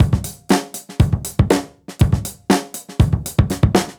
Index of /musicradar/dusty-funk-samples/Beats/120bpm
DF_BeatD_120-03.wav